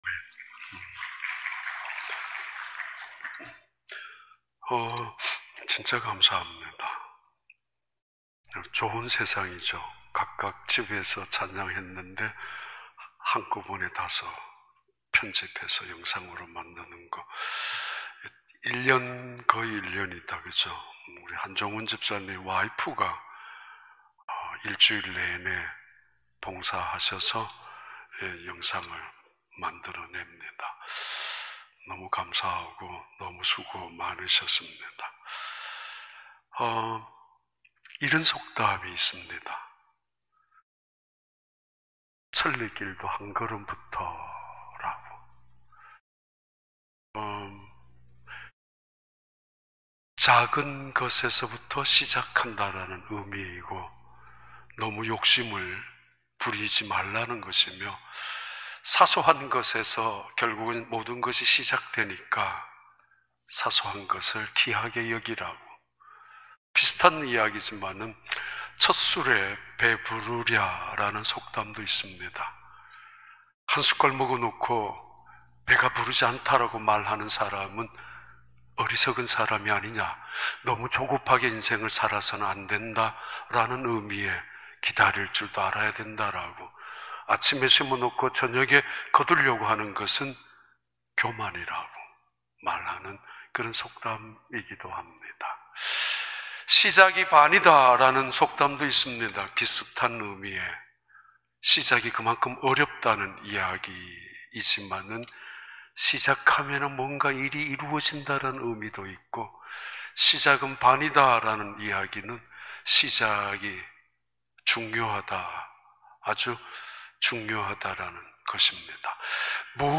2021년 1월 17일 주일 4부 예배